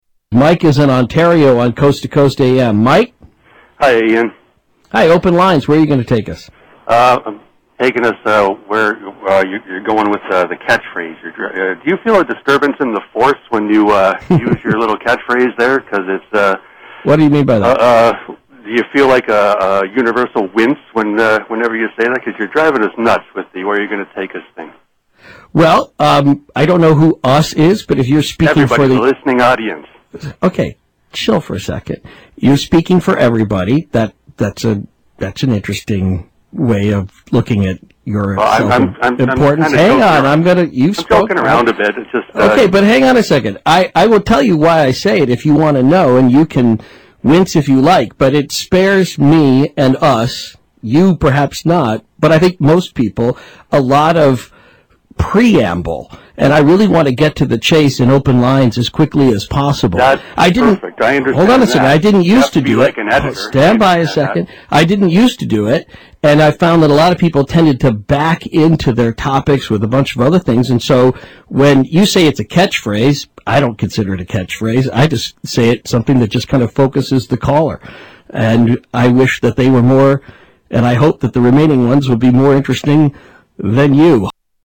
Category: Radio   Right: Personal
Tags: Coast to Coast AM Call Ins Coast to Coast AM Coast to Coast AM Calls George Noory Radio show